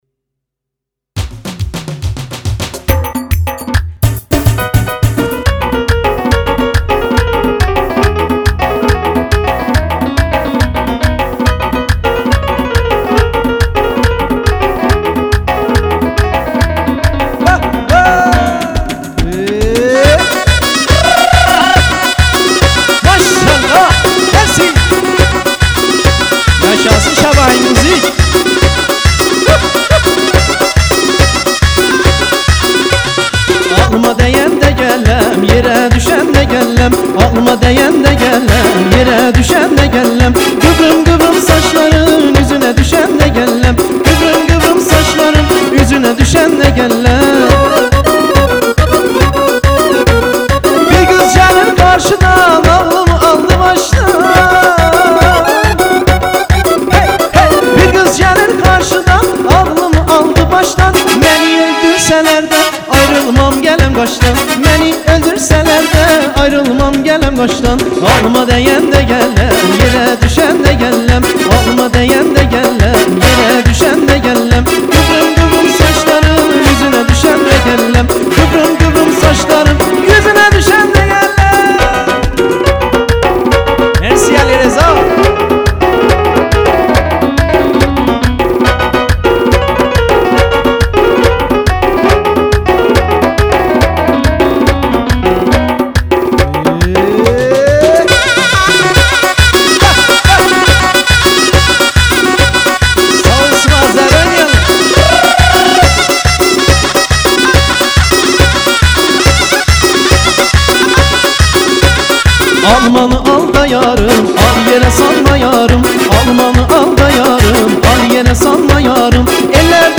ترکی آذری شاد عروسی